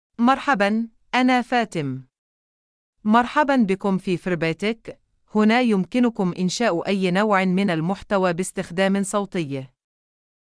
Fatima — Female Arabic (United Arab Emirates) AI Voice | TTS, Voice Cloning & Video | Verbatik AI
Fatima is a female AI voice for Arabic (United Arab Emirates).
Voice sample
Fatima delivers clear pronunciation with authentic United Arab Emirates Arabic intonation, making your content sound professionally produced.